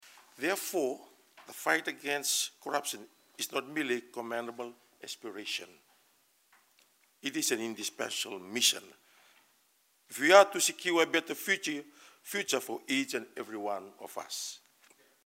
In his address to the attendees, Turaga highlights that this inaugural conference provides an ideal platform to share success stories and best practices in the fight against corruption.